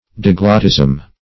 diglottism - definition of diglottism - synonyms, pronunciation, spelling from Free Dictionary
Search Result for " diglottism" : The Collaborative International Dictionary of English v.0.48: Diglottism \Di*glot"tism\, n. [Gr.